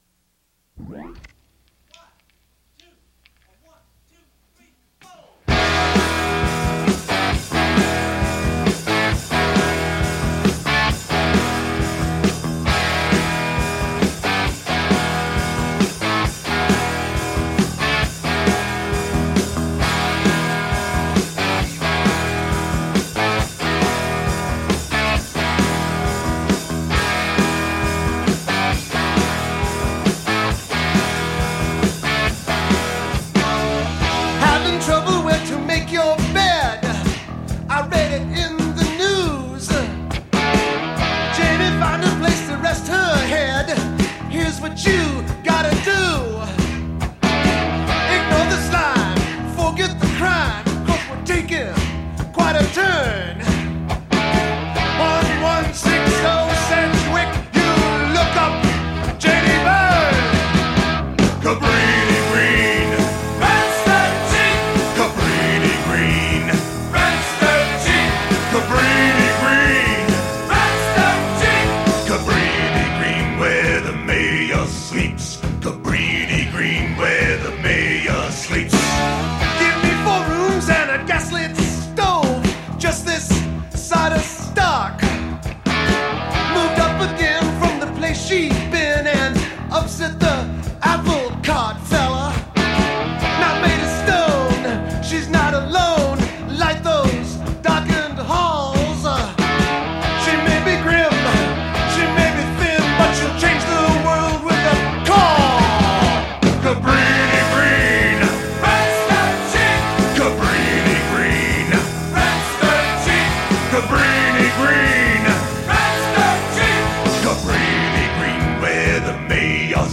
parody song